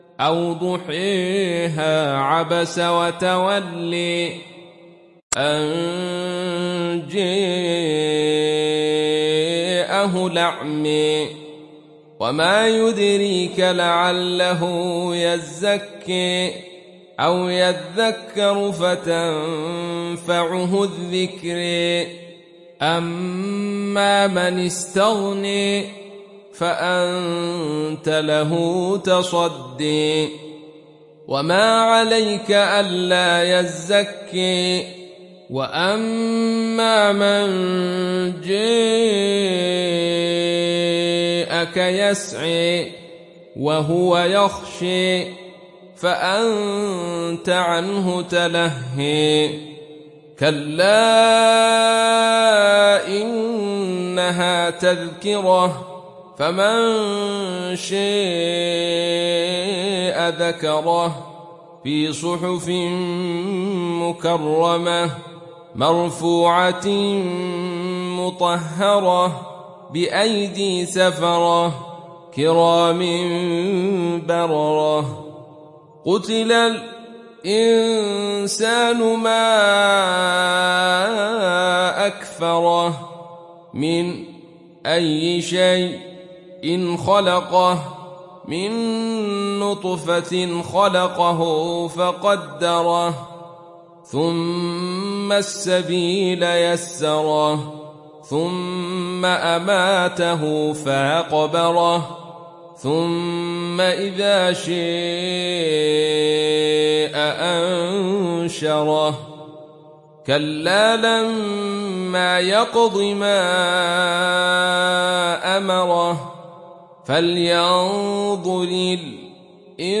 دانلود سوره عبس mp3 عبد الرشيد صوفي روایت خلف از حمزة, قرآن را دانلود کنید و گوش کن mp3 ، لینک مستقیم کامل